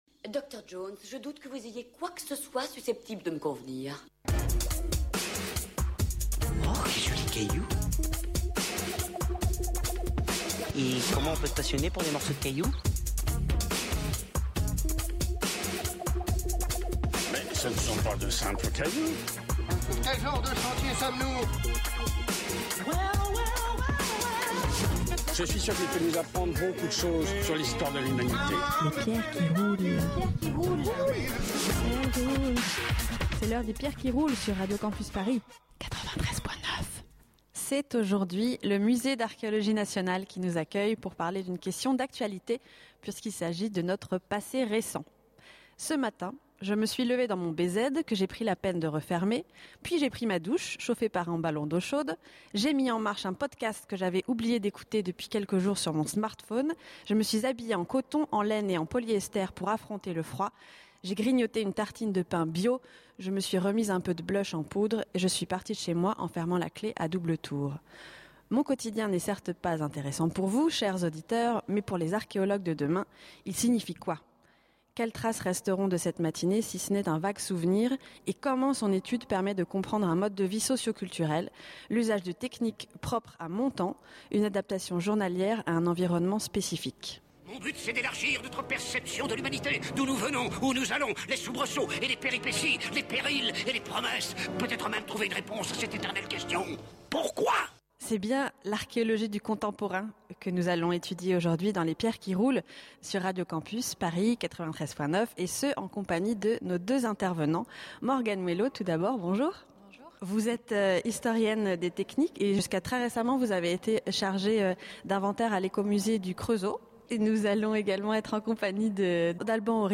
Les Pierres qui roulent reviennent au Musée d’Archéologie Nationale ce samedi 27 février pour une émission spéciale sur les patrimoines archéologiques modernes et contemporains…